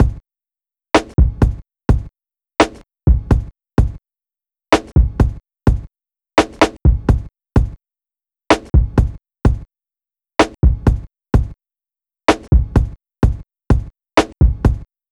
Track 10 - Drum Break 01.wav